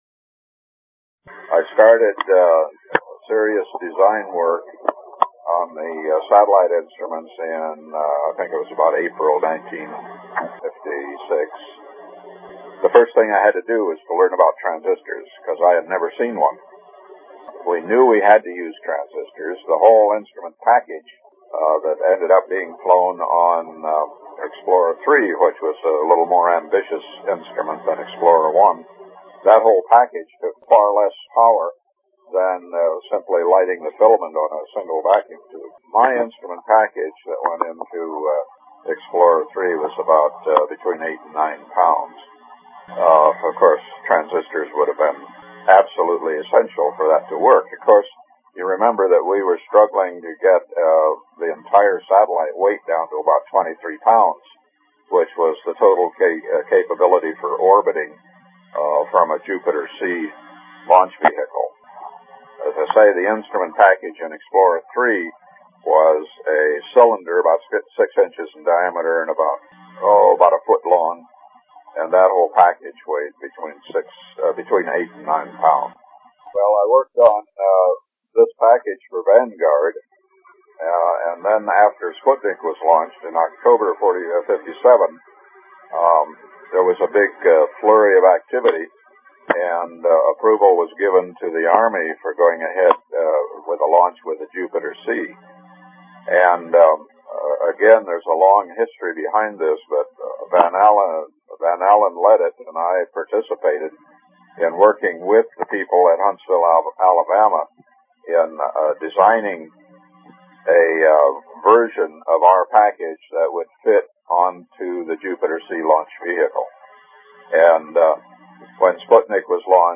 A Transistor Museum Interview